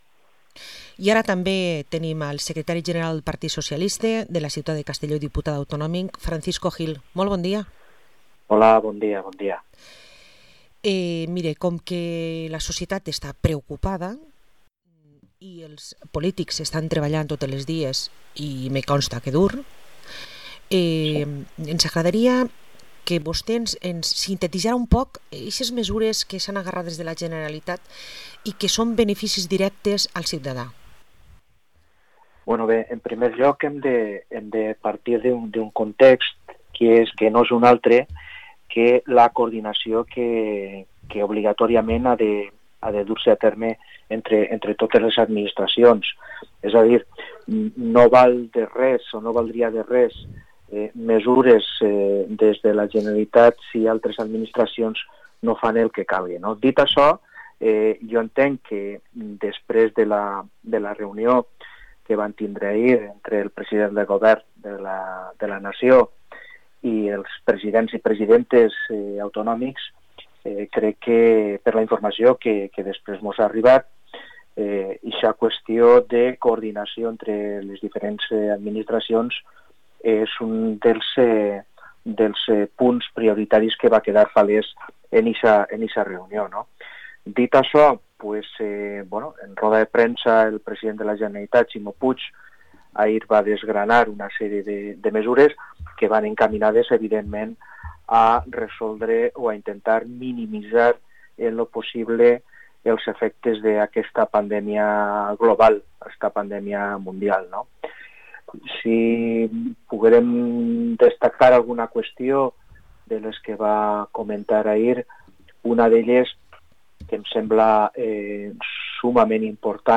Entevista a Paco Gil, diputat autonomic pel PSPV-PSOE